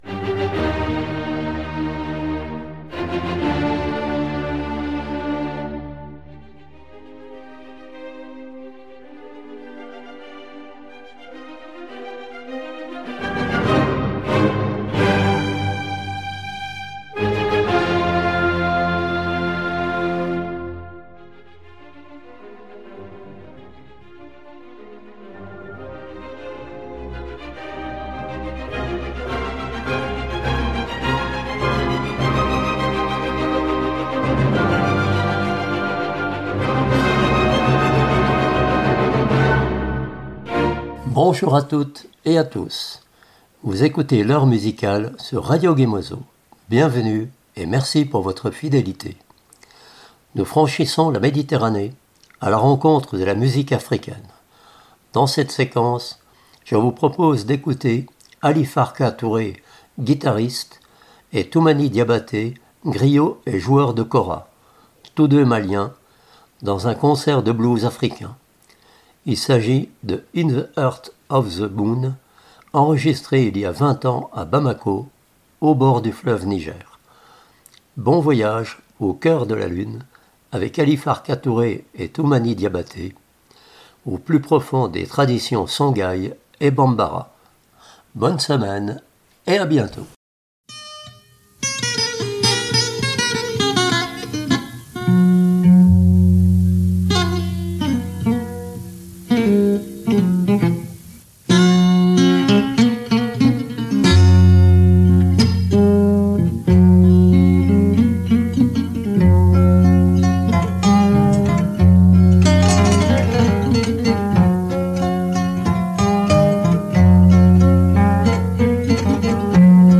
kora